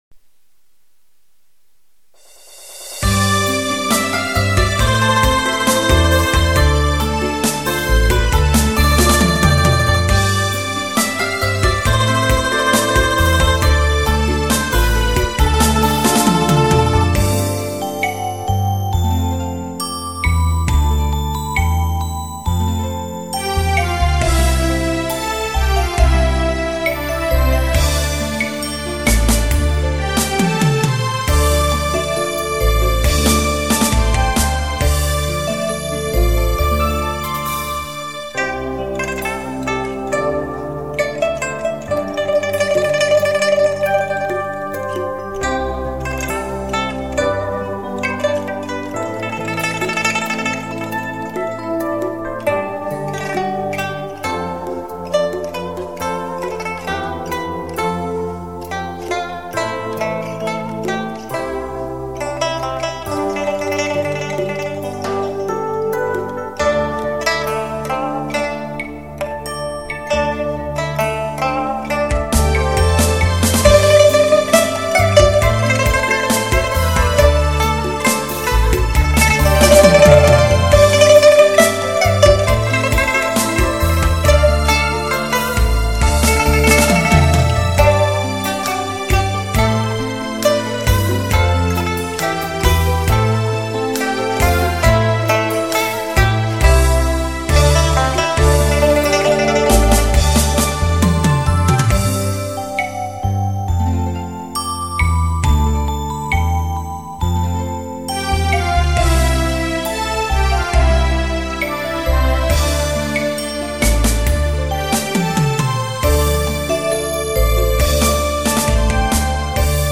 琵琶） 为低音质MP3